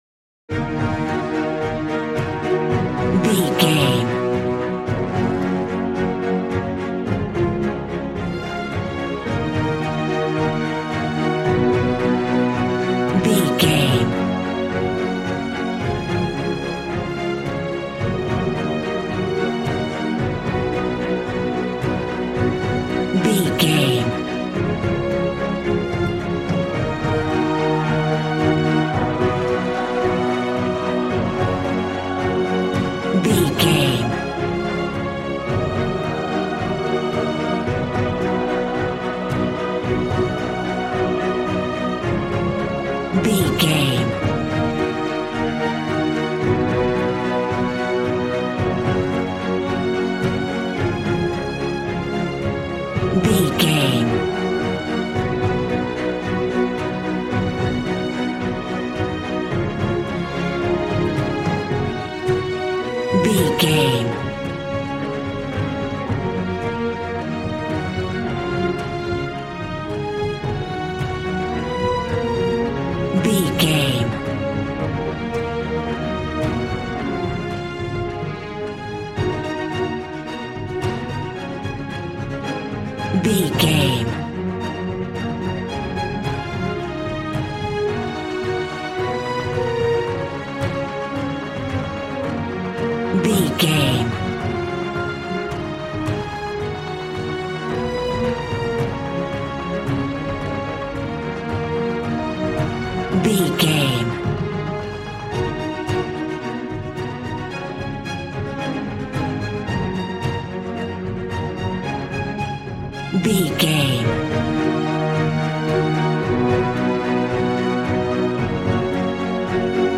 G♭
suspense
piano
synthesiser